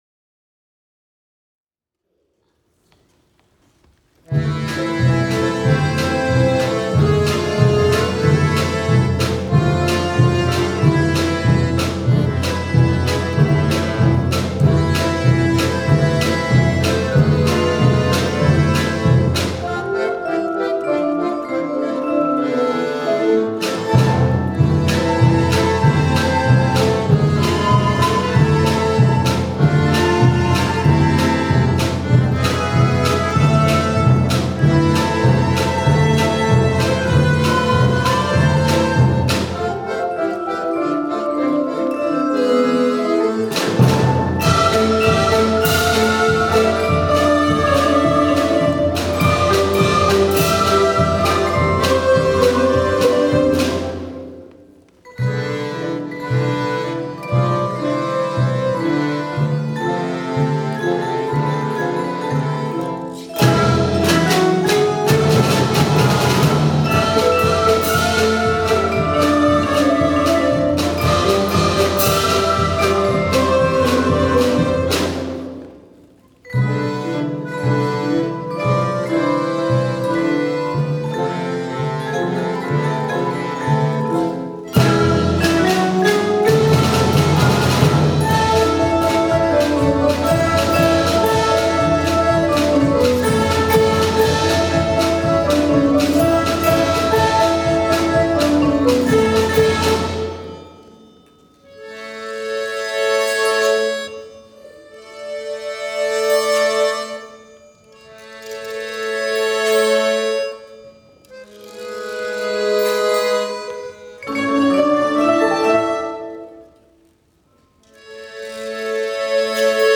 昨日の校内音楽会で録音した、合唱・合奏を音声ファイルでお届けします。
五年生
【合奏】